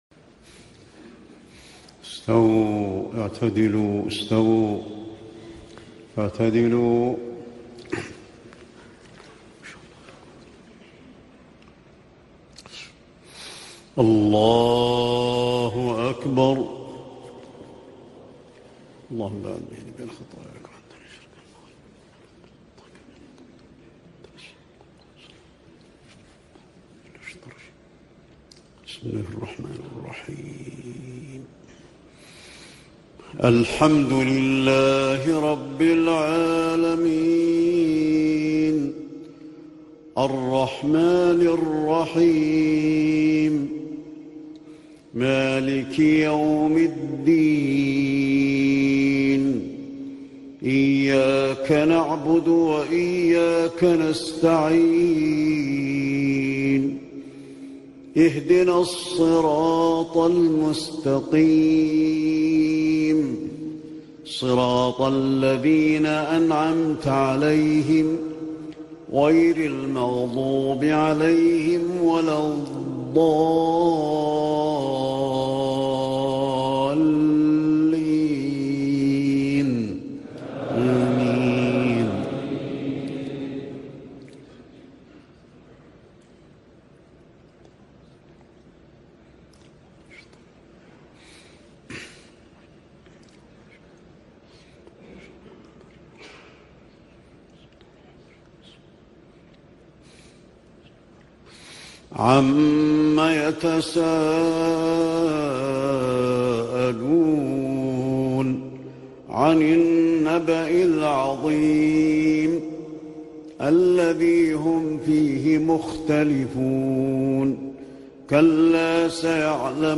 صلاة العشاء للشيخ علي الحذيفي من جامع زعبيل دبي يوم الاثنين 19 رمضان 1439هـ سورة النبأ كاملة > تلاوات و جهود الشيخ علي الحذيفي > تلاوات وجهود أئمة الحرم النبوي خارج الحرم > المزيد - تلاوات الحرمين